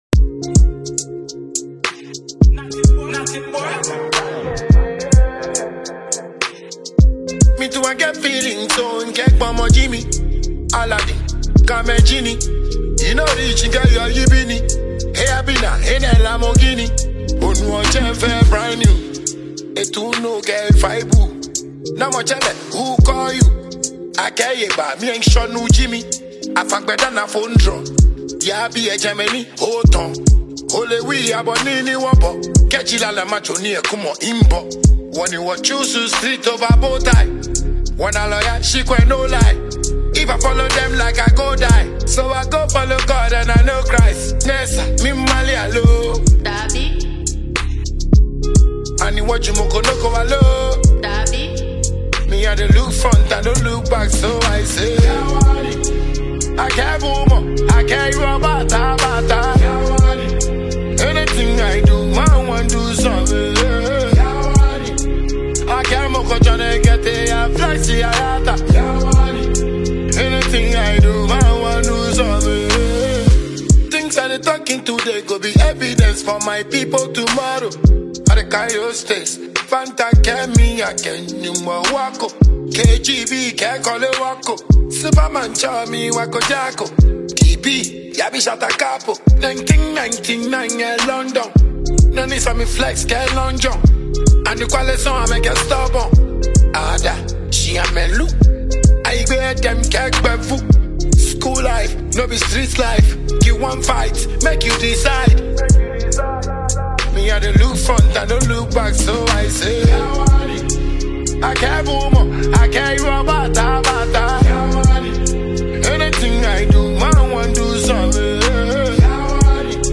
Ghanaian dancehall heavyweight
blends infectious rhythms with bold lyrics